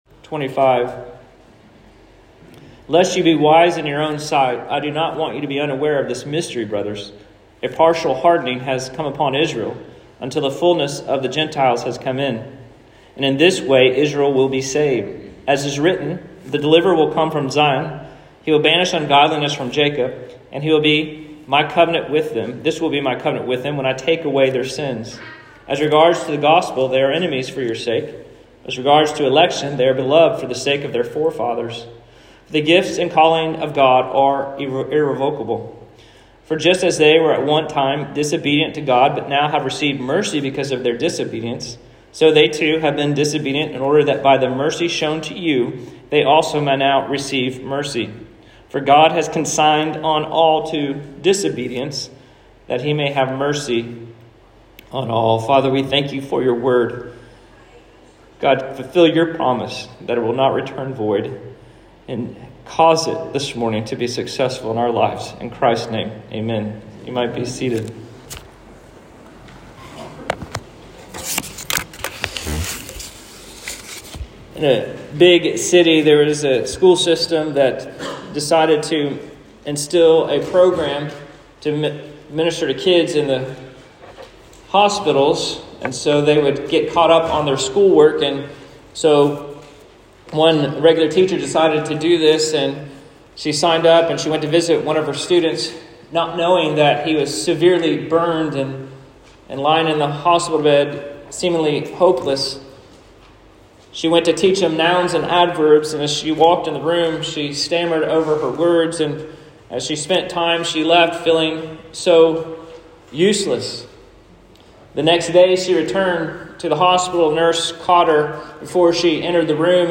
Sermons | Christ Community Church